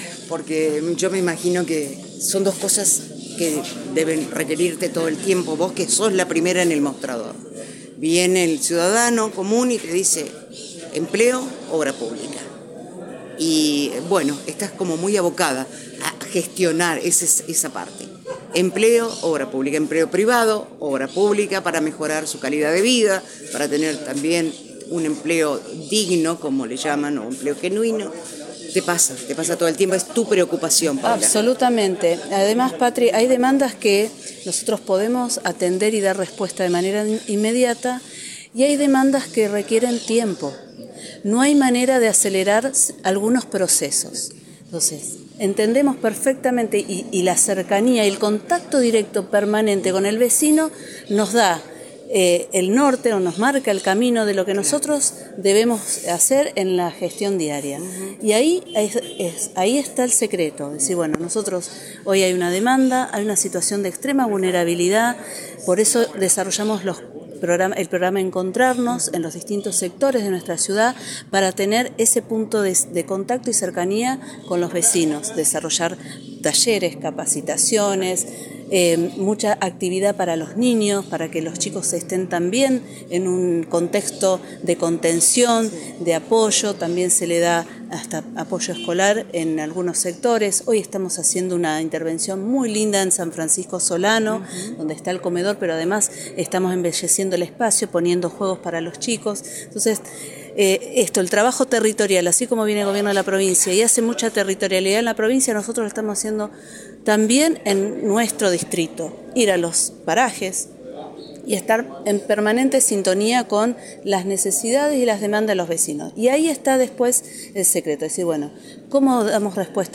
En dialogo con FM ACTIVA, la Intendente de Vera, Paula Mitre, se refirió a las distntas acciones que está realizando el municipio y se refirió a la necesidad que el gobierno provincial ayude en muchas de las cuestiones que necesita la ciudad y que por razones económicas no las puede realizar y al respectó destacó que la administración del gobernador Pullaro tiene a Vera en su agenda y fue producto del dialogo, de poder llegar a los consensos, del respeto y de las buenas relaciones.
Paula Mitre – Intendente de la Ciudad de Vera